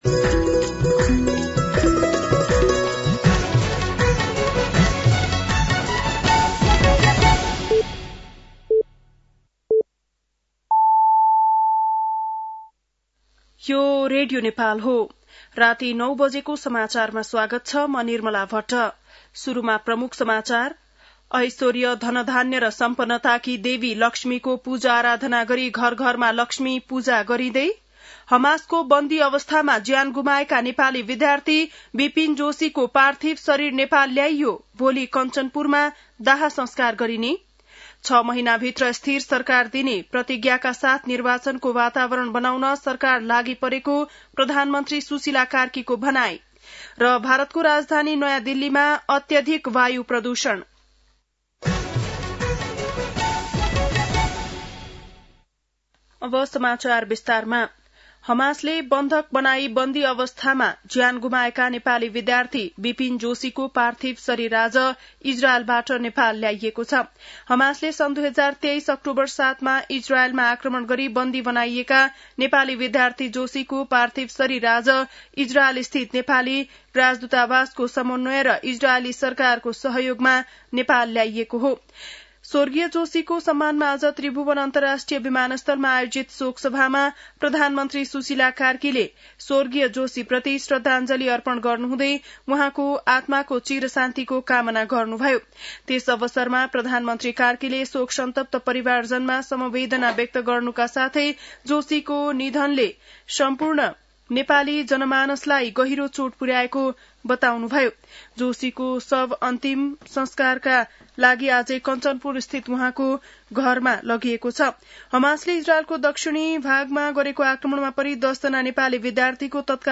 बेलुकी ९ बजेको नेपाली समाचार : ३ कार्तिक , २०८२
9-PM-Nepali-NEWS-7-03.mp3